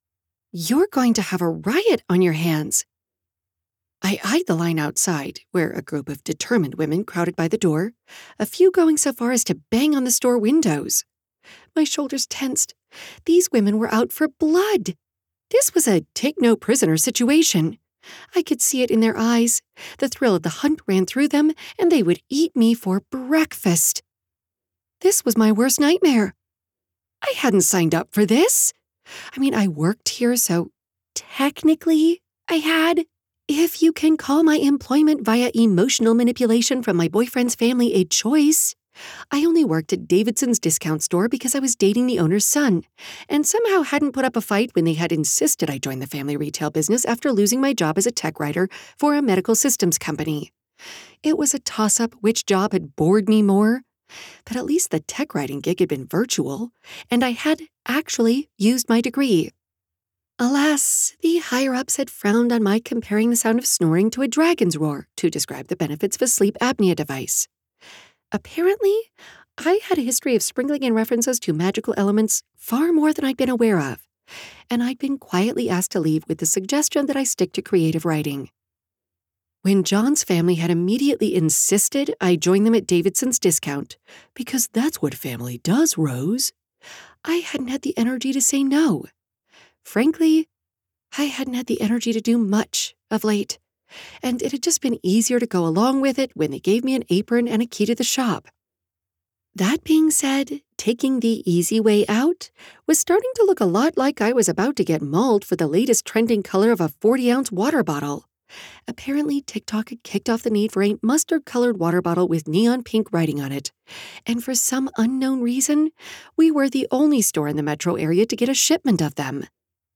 • Audiobook